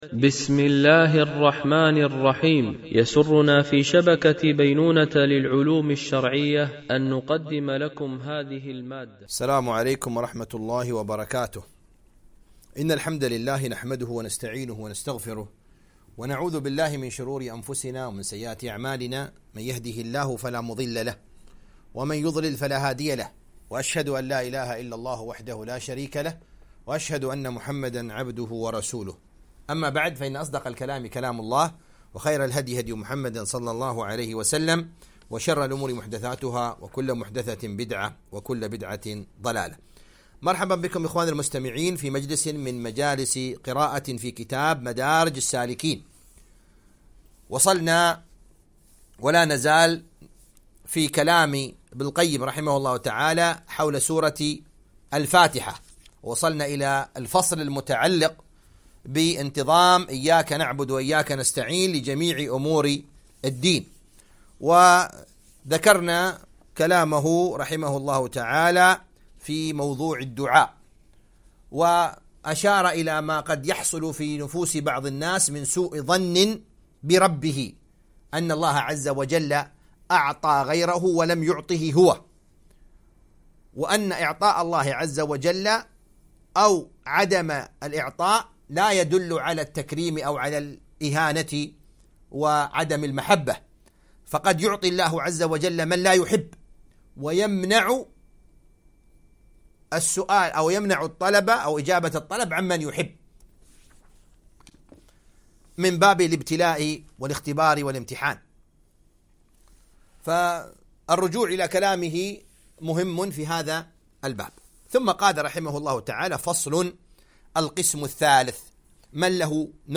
قراءة من كتاب مدارج السالكين - الدرس 11